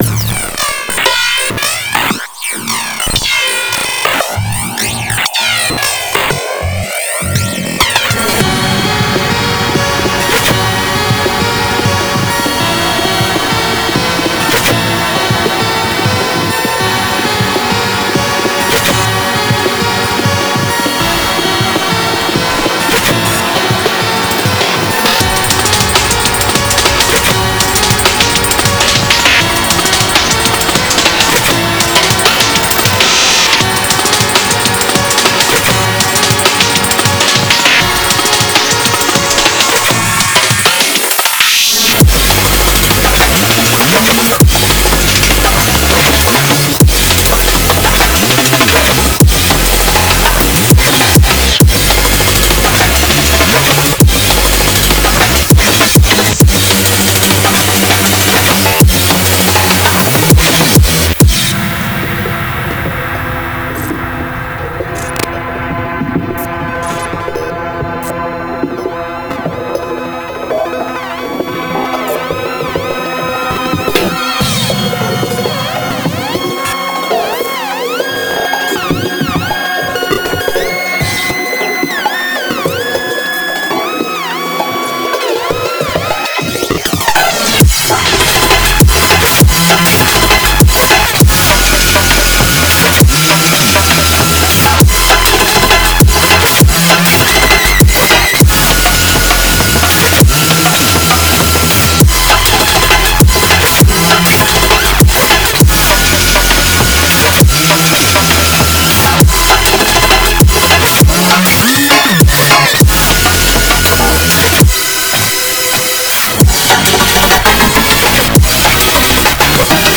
trippy
colorful
Drum N Bass